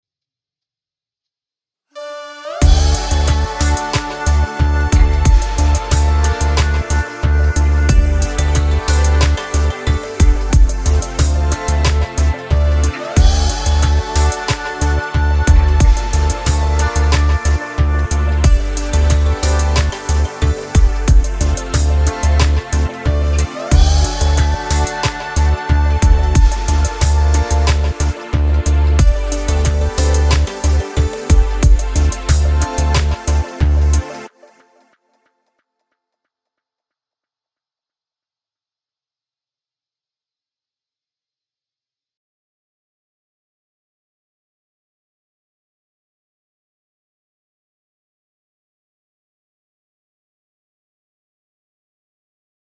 Now let's hear that same chord progression, but we'll simply keep D as a bass pedal tone the whole time.
All of the previous chords, pedaled over D
Same Progression With D Pedal